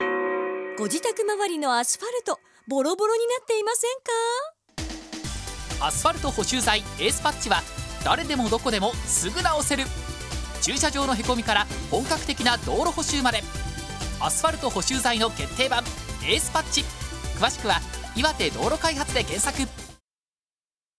ラジオCMのお知らせ📻【常温合材エースパッチ】
この度、弊社が取り扱う常温合材エースパッチのCMが、2月18日から3月17日までの期間、IBCラジオで放送されることになりました。